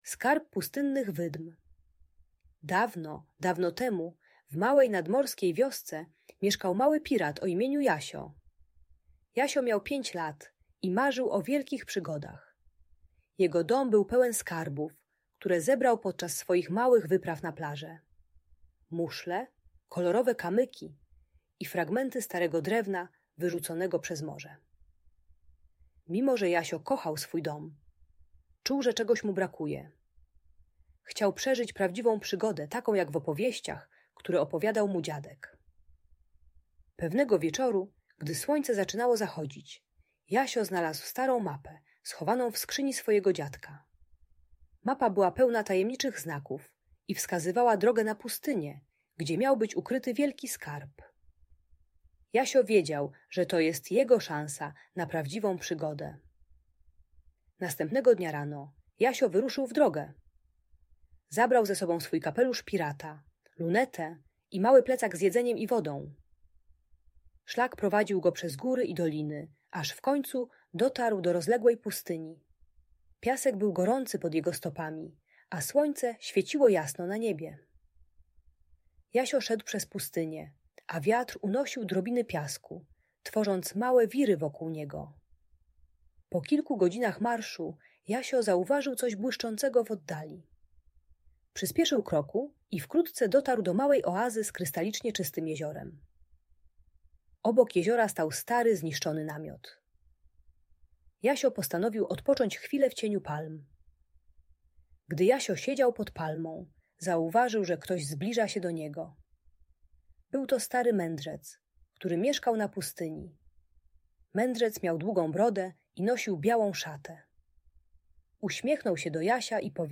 Przygody Jasia: Skarb Pustynnych Wydm - Audiobajka